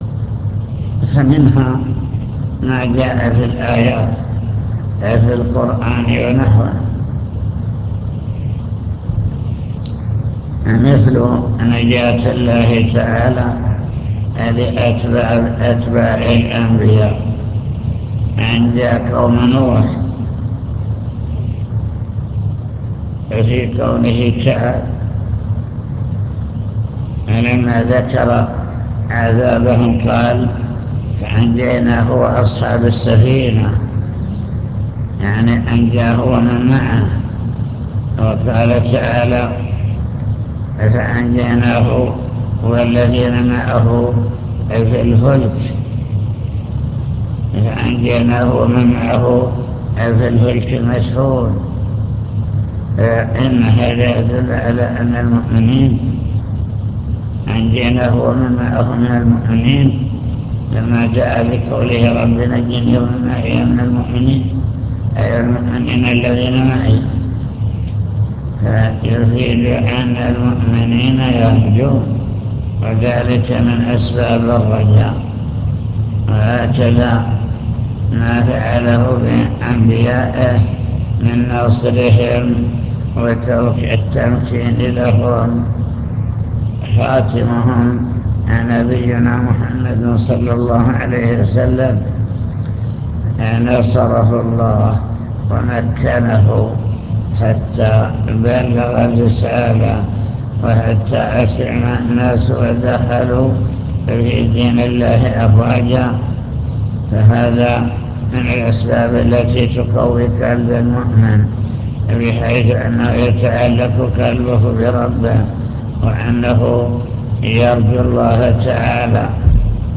المكتبة الصوتية  تسجيلات - محاضرات ودروس  كتاب التوحيد للإمام محمد بن عبد الوهاب باب قول الله تعالى 'أفأمنوا مكر الله فلا يأمن مكر الله إلا القوم الخاسرون'